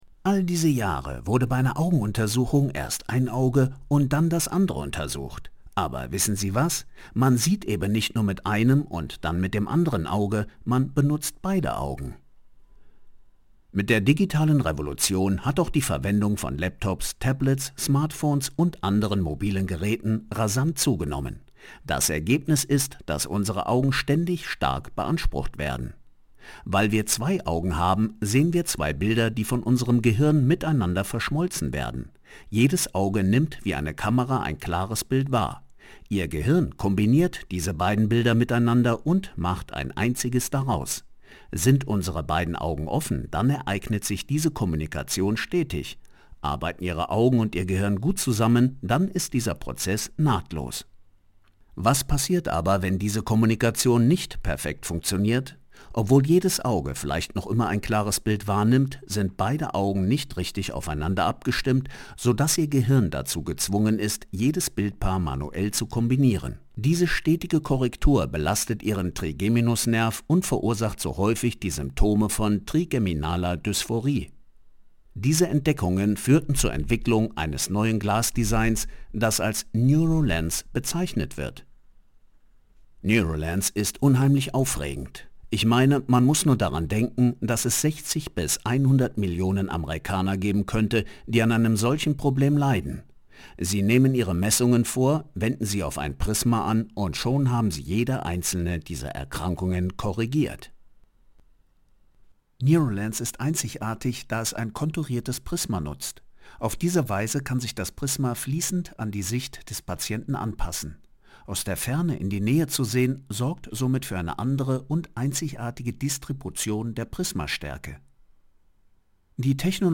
Professioneller deutscher Sprecher / voice-over.
Sprechprobe: Industrie (Muttersprache):
professional voice over, german speaking narrator (voice over, dubbing actor, video games, audio book, radio drama, docoumentary, advertising, poetry etc.).